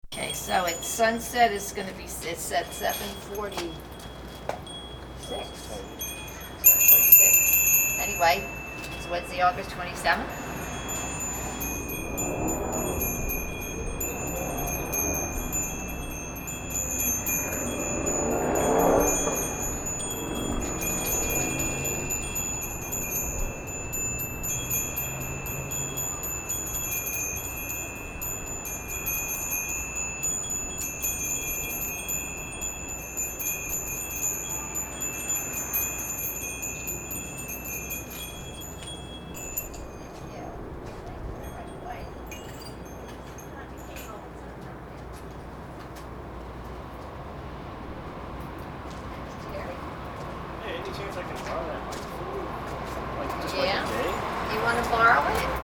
It was so windy out on the street that I set up the microphone near the house and we stood by the curb to ring.
We rang long enough (audio) for a plane to pass by and then I started to pack up.